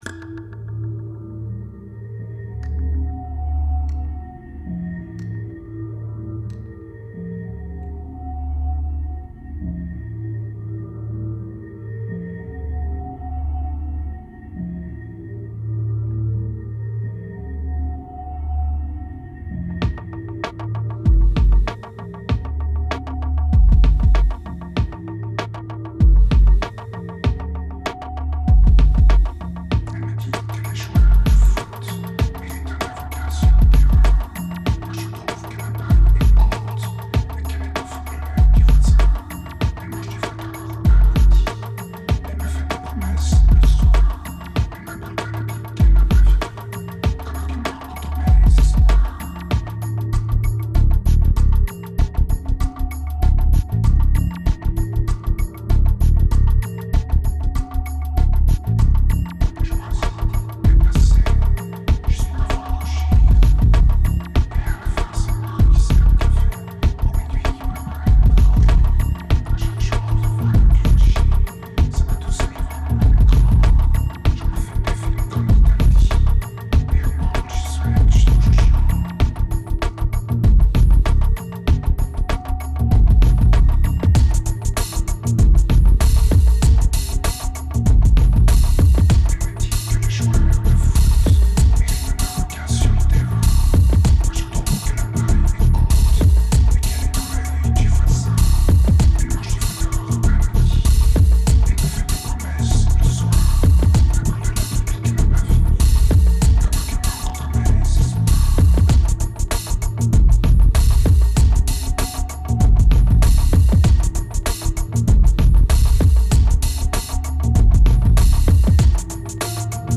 2201📈 - -11%🤔 - 97BPM🔊 - 2010-09-12📅 - -180🌟